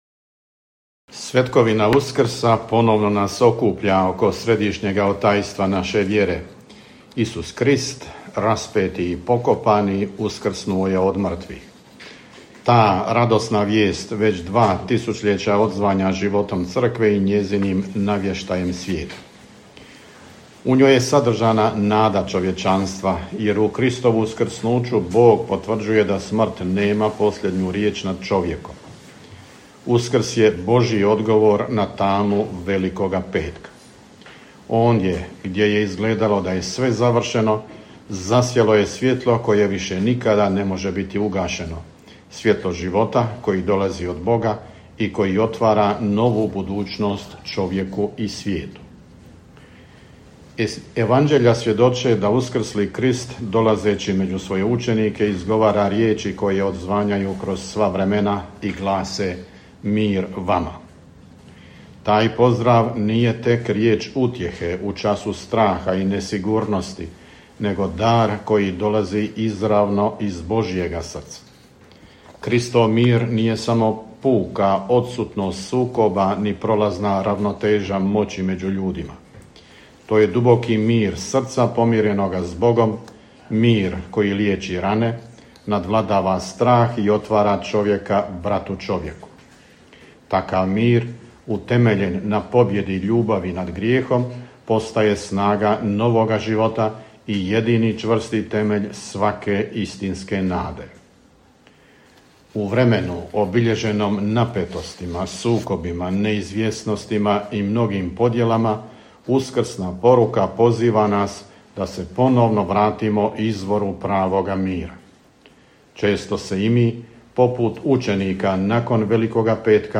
Vrhbosanski nadbiskup metropolit mons. Tomo Vukšić na susretu s novinarima, koji je održan u subotu 4. travnja u Nadbiskupskoj rezidenciji u Sarajevu, pročitao je tekst poruke i uputio uskrsnu čestitku vjernicima.